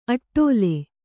♪ aṭṭōle